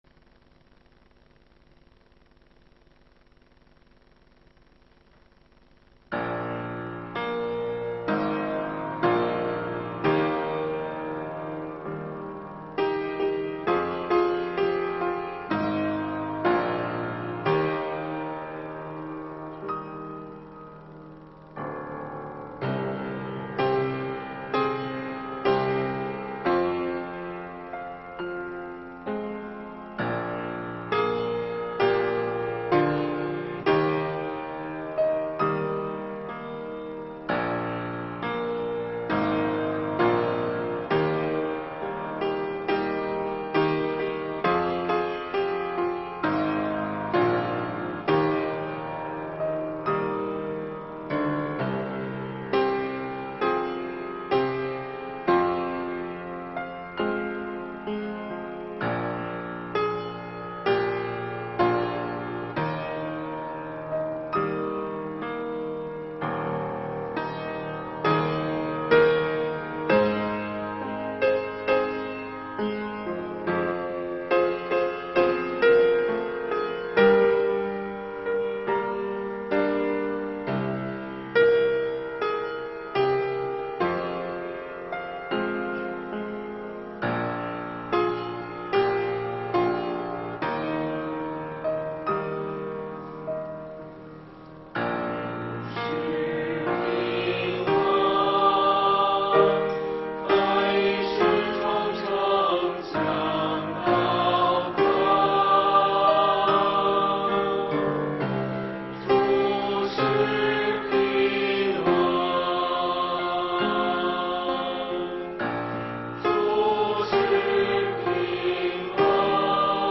证道内容： 马太福音2章1-12节的经文告诉我们，当耶稣降生在犹太的伯利恒时，有一些人从遥远的东方而来，他们追随着一颗星的引导，不惜长途跋涉，为要敬拜那位新生王；而又有一些人，他们十分清楚新生王就降生在不远的伯利恒，却置若罔闻；还有人听说新生王降生了，竟暗下决心要除掉他。